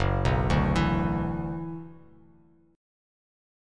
F#7sus4#5 chord